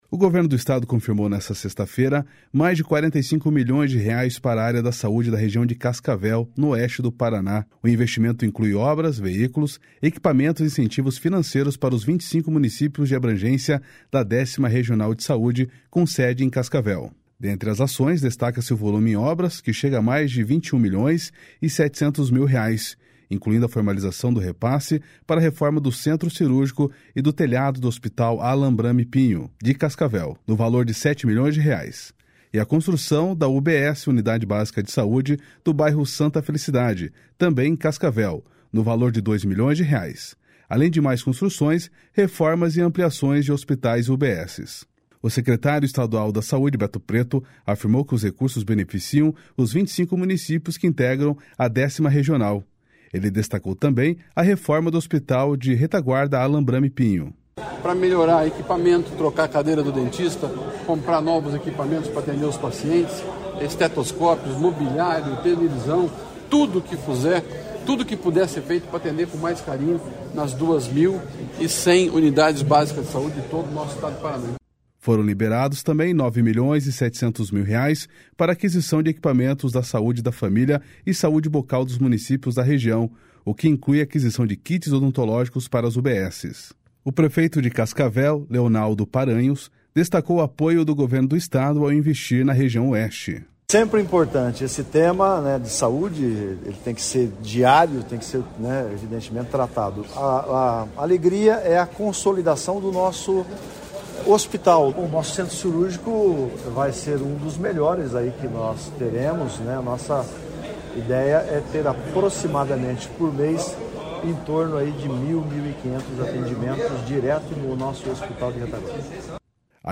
//SONORA LEONALDO PARANHOS//